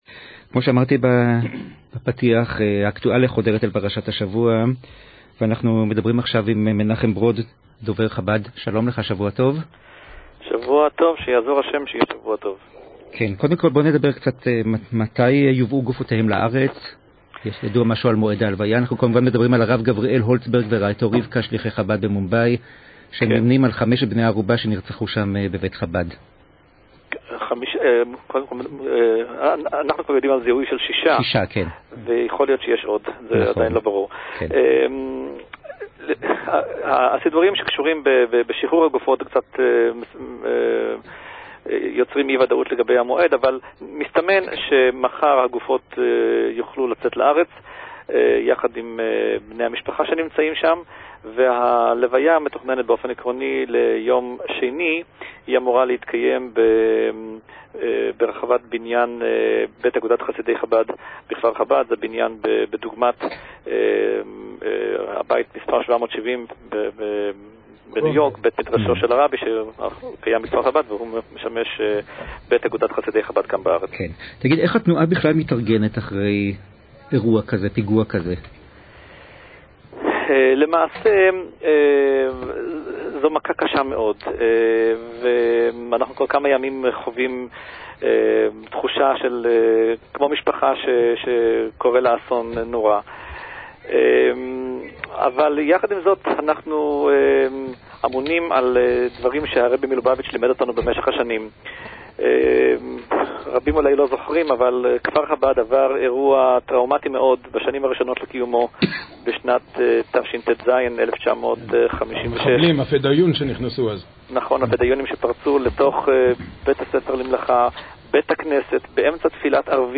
בתכנית מלווה מלכה ברשת ב' של קול ישראל במוצ"ש